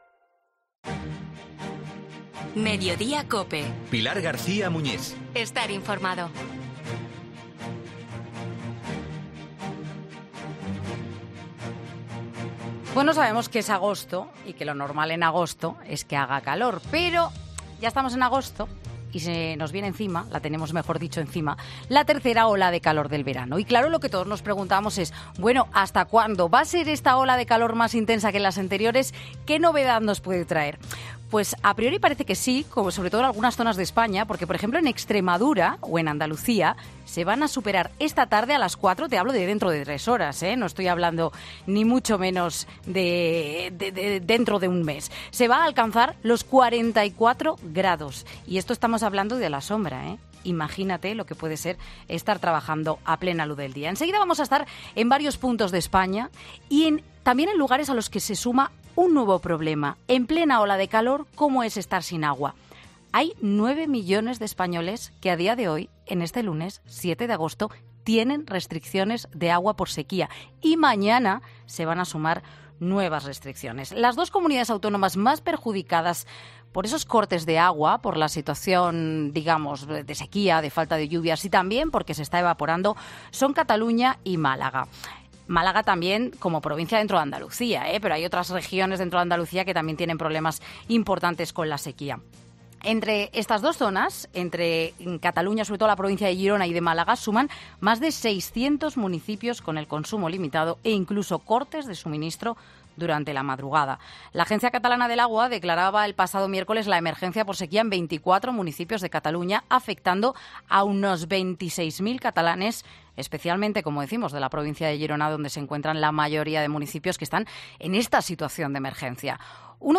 Raúl Vallejo, alcalde de El Borge, en Mediodía COPE ante la falta de agua y la nueva ola de calor